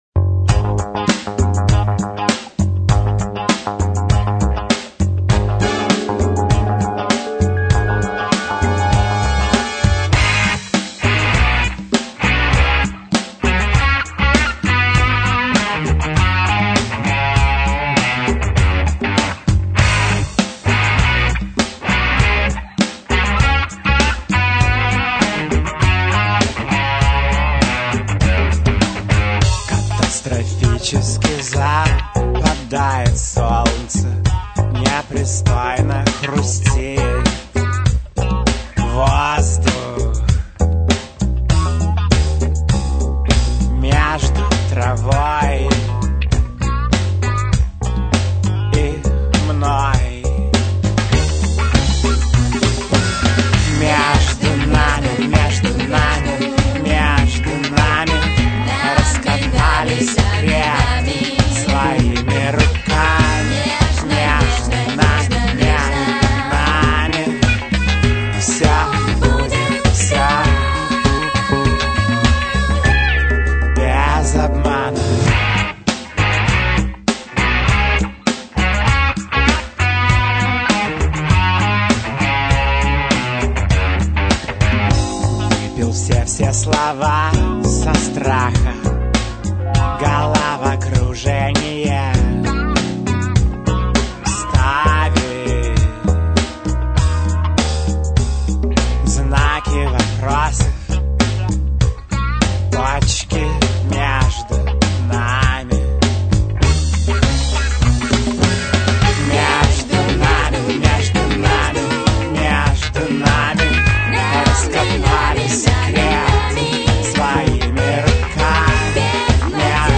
- вокал,  клавишные
- бас,  клавишные
- ударные,  программирование
- гитары,  клавишные